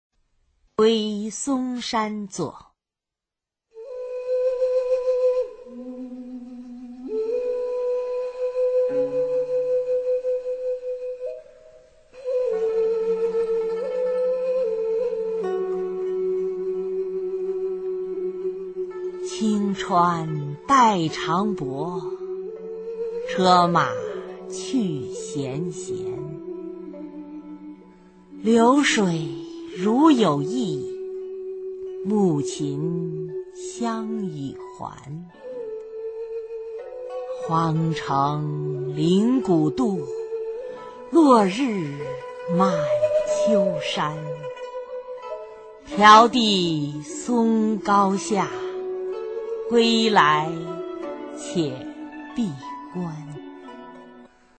[隋唐诗词诵读]王维-归嵩山作 配乐诗朗诵